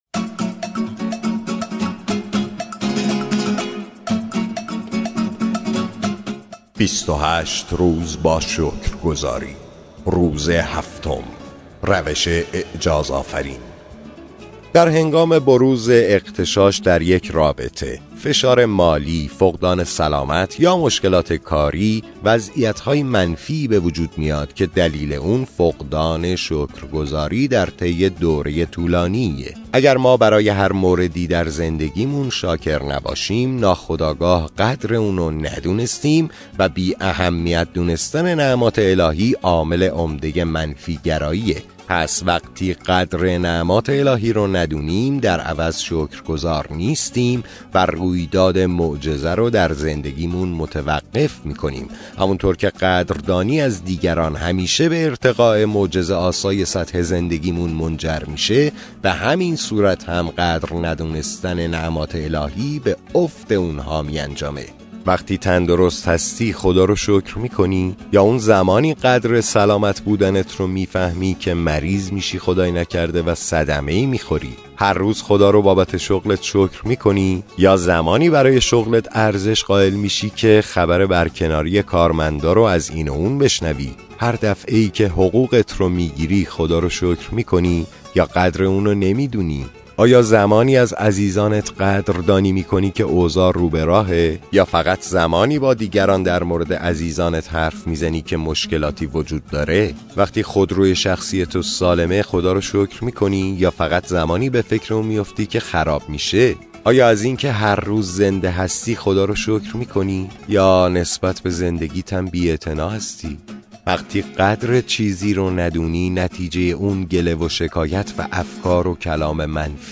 کتاب صوتی